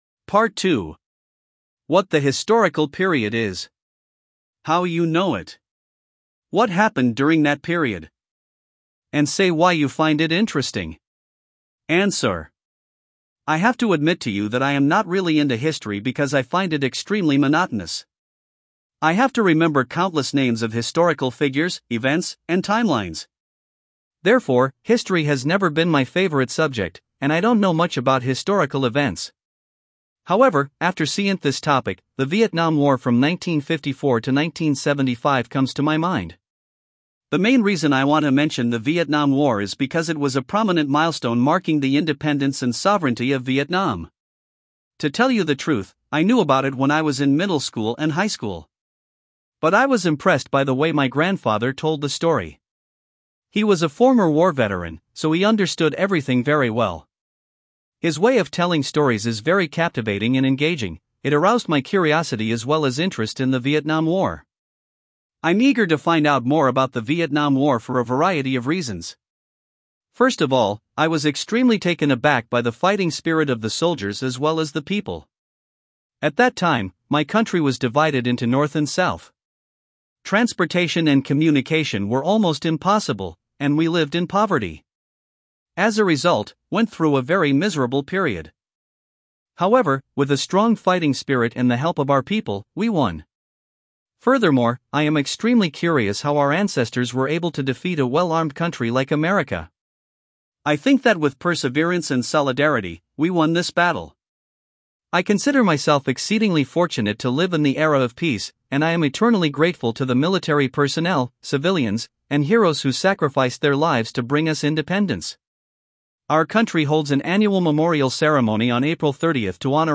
Guy (English US)